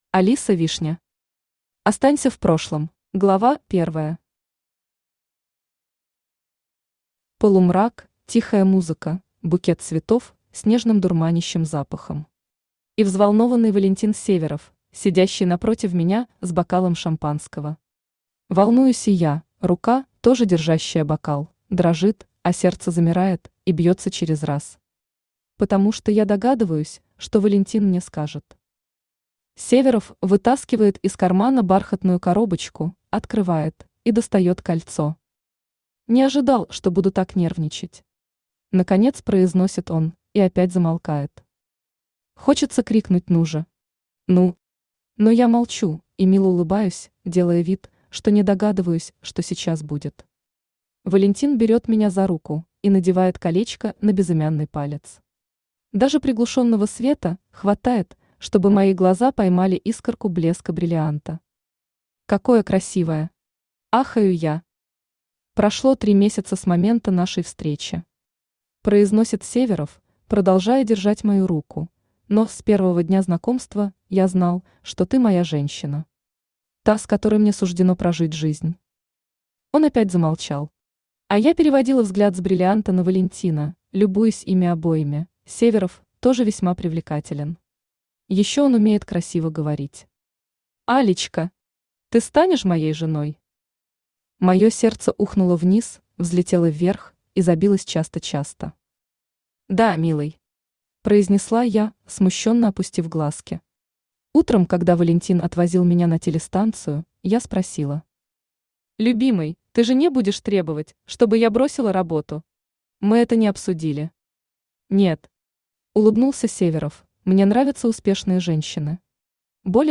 Аудиокнига Останься в прошлом | Библиотека аудиокниг
Aудиокнига Останься в прошлом Автор Алиса Вишня Читает аудиокнигу Авточтец ЛитРес.